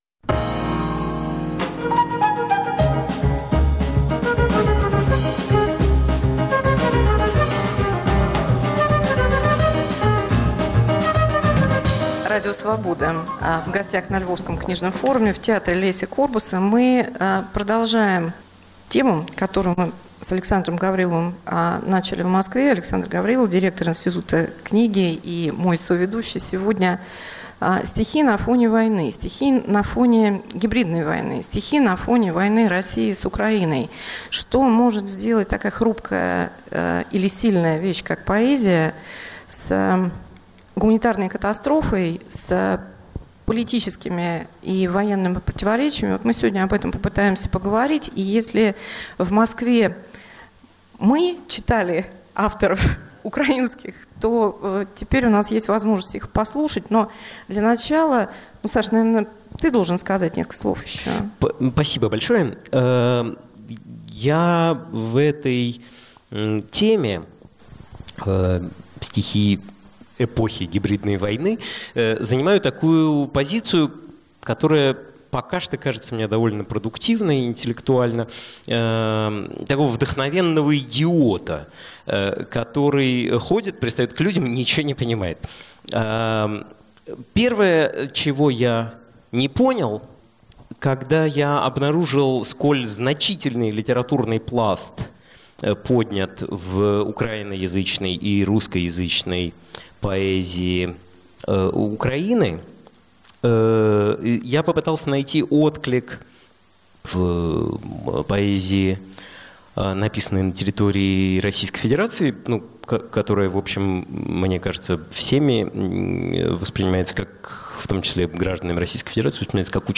Украинские и русские поэты – о войне на востоке Украины. Чтения на Львовском книжном форуме, театр Леся Курбаса. Искусство и политика, война и культура.